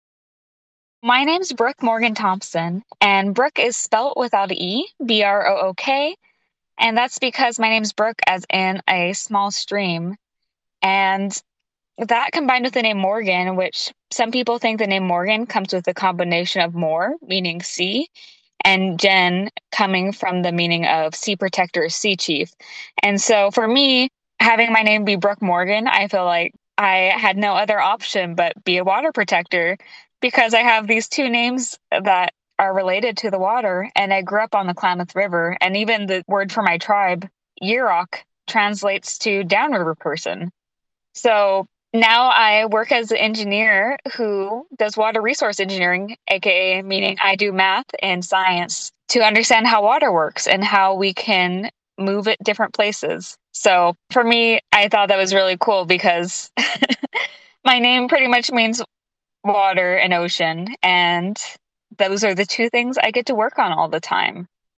Audio Name Pronunciation
Educator Note: This primary source recording gives insight into a book creator and is not directly tied to a specific book.